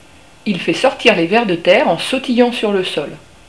Le merle noir